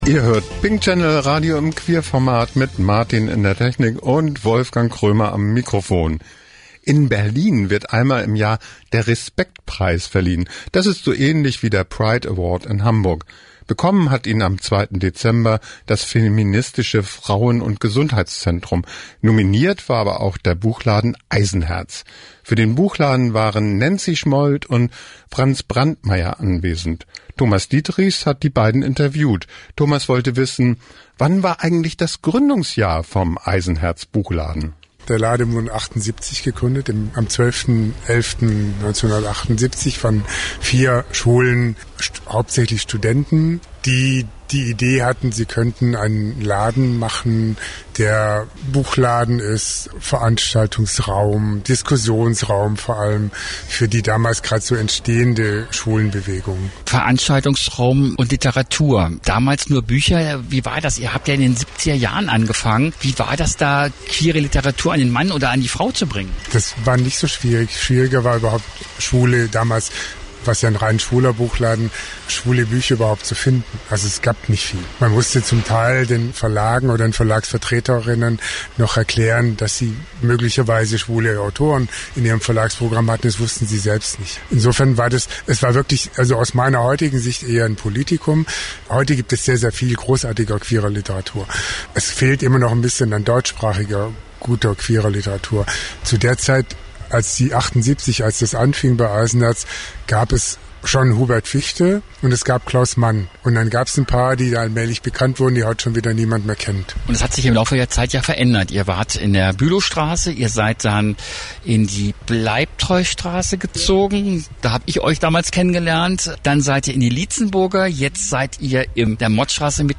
Bei der Respektpreisverleihung in Berlin
ein Interview